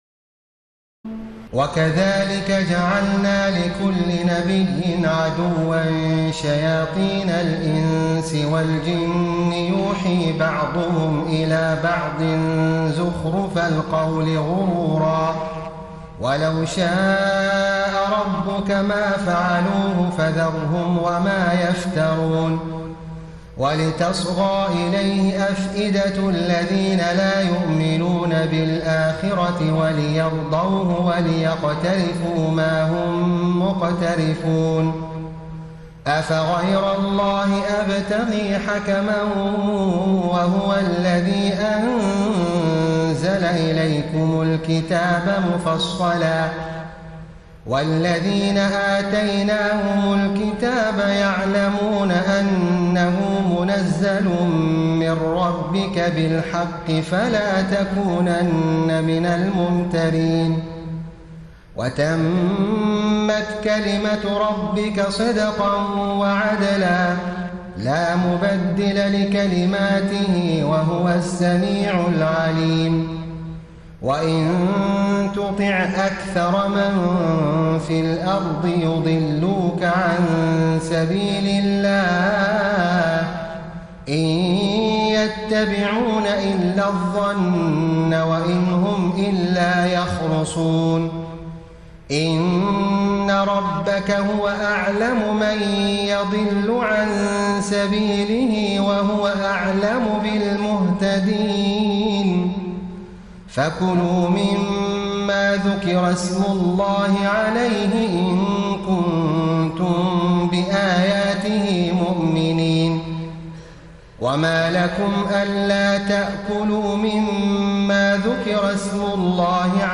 تراويح الليلة الثامنة رمضان 1433هـ من سورة الأنعام (112-165) Taraweeh 8 st night Ramadan 1433H from Surah Al-An’aam > تراويح الحرم النبوي عام 1433 🕌 > التراويح - تلاوات الحرمين